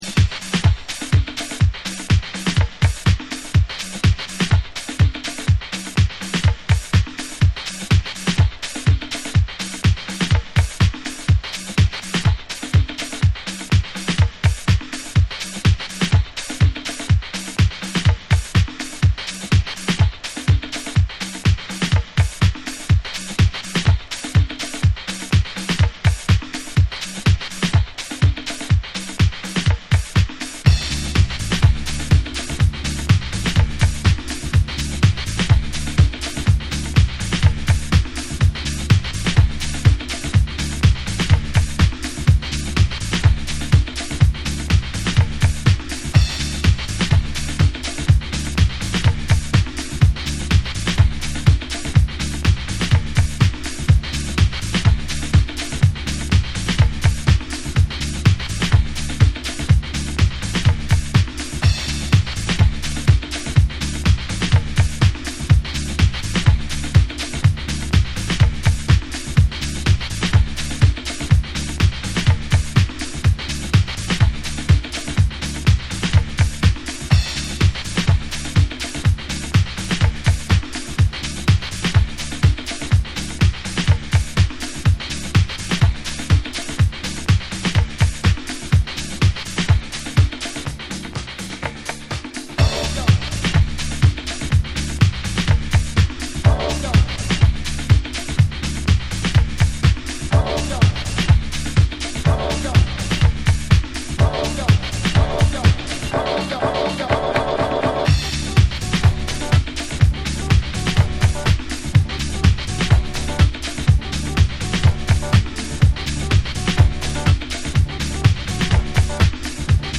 頭から尻尾までドントストップなグルーヴキープ力のあるサンプリングハウス。サンプリング粒子飛び散るグレイトディスコハウス。